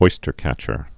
(oistər-kăchər)